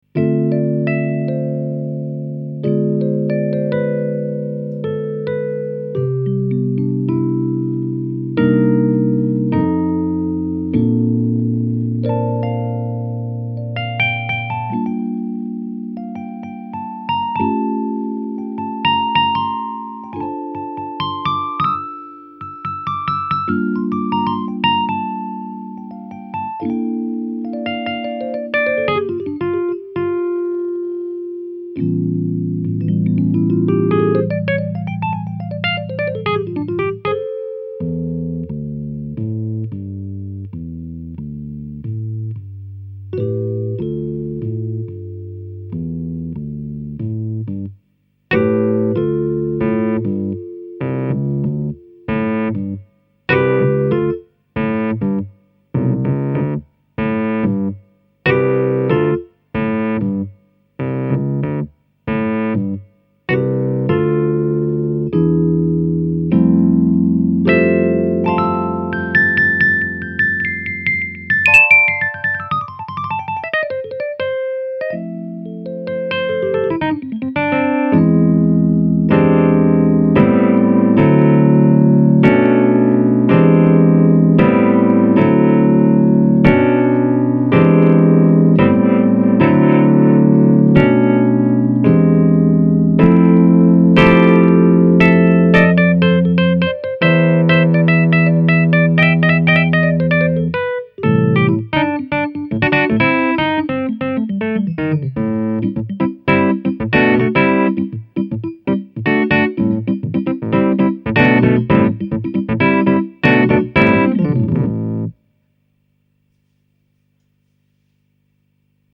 mrray73_amp.mp3